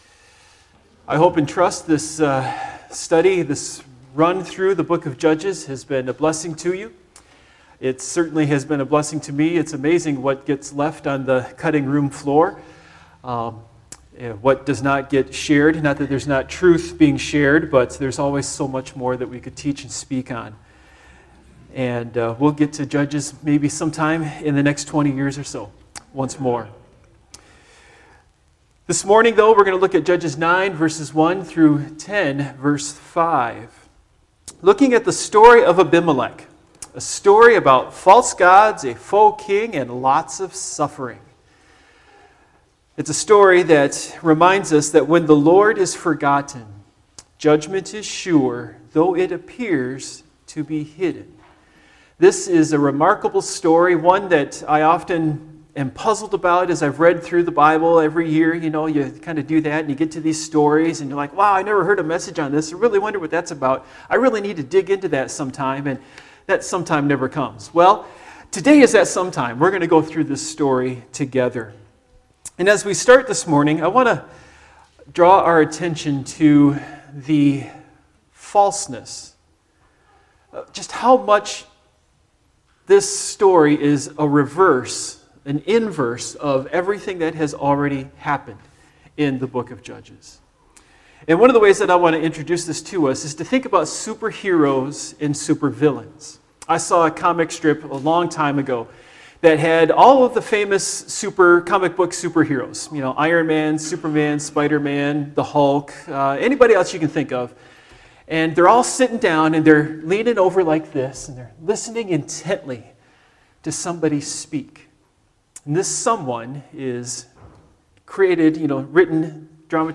Passage: Judges 9-10:5 Service Type: Morning Worship